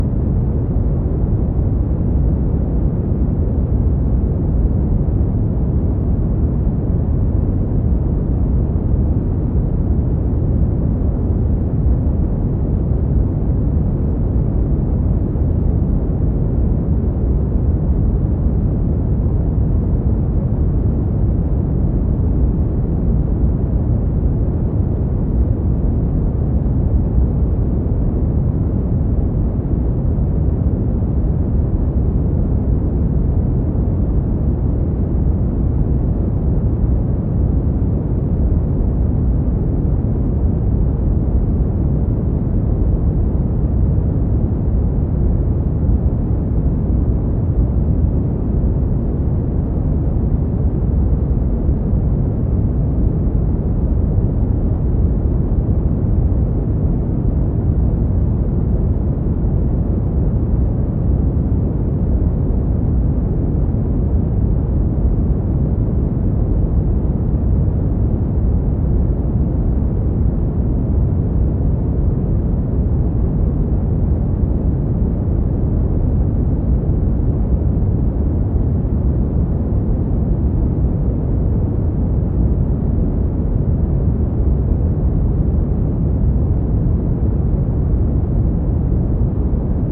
Звуки коричневого шума
Глубокие низкие частоты создают успокаивающий эффект, помогая отвлечься от стресса.